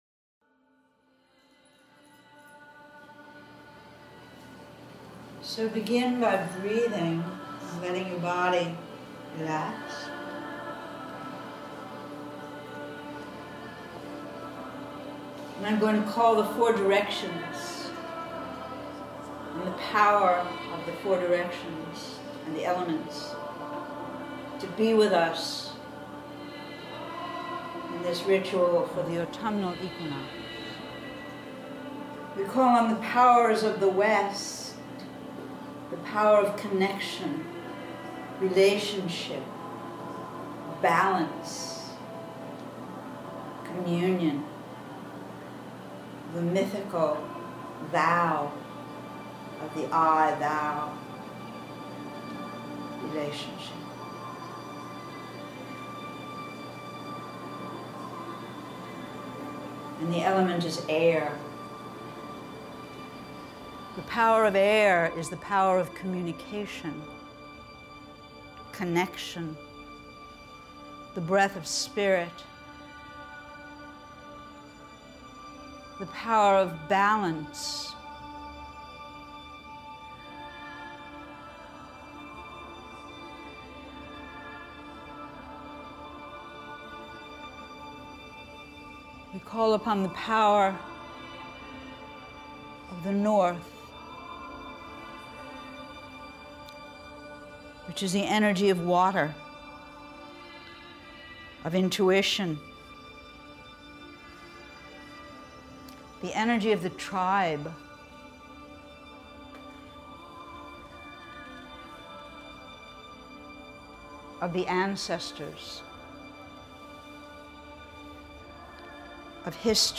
They are recorded live in class.